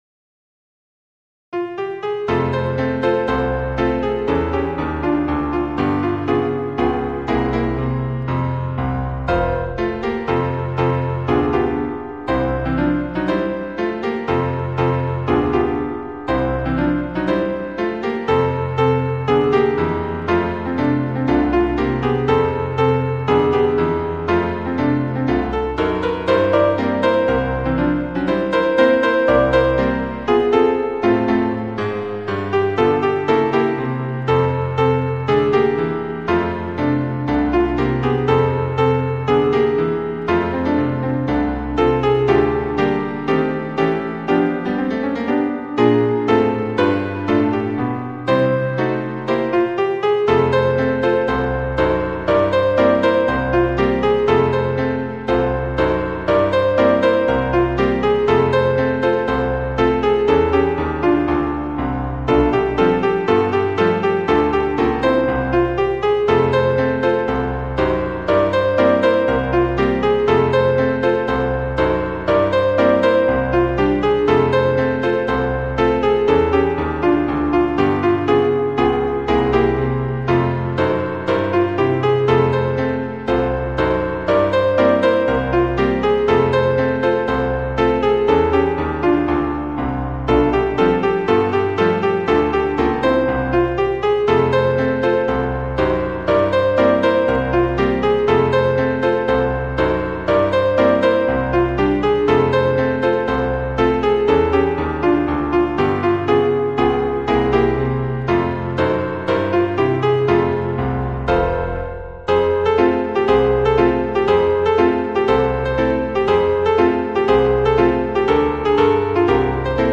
We selected instruments that sounded like upright Victorian pianos; in many cases, the lyrics line is played back as a honky-tonk piano, while the supporting bass and treble lines were kept to more sedate parlor pianos. The reverb is what we imagine these tunes would have sounded like had then been played in the Hotel Florence (with its Minton tile lobby) in 1885.